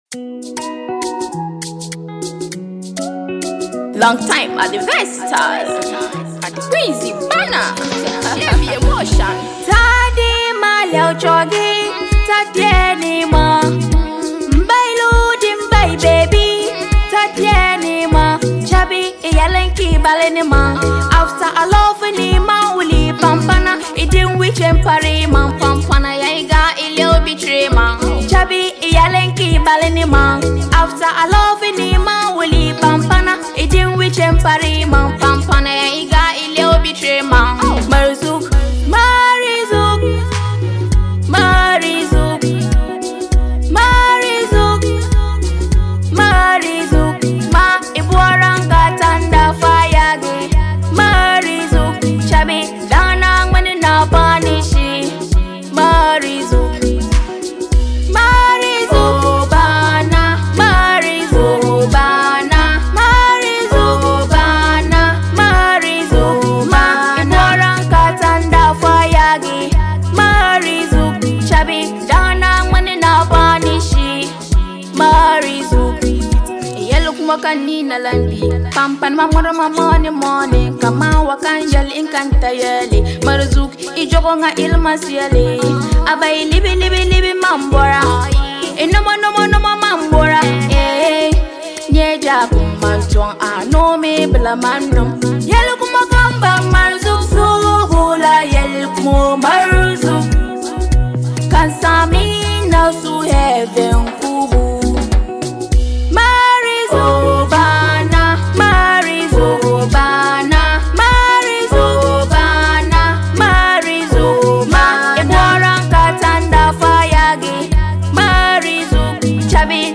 Ghana Music Music
Ghanaian female musician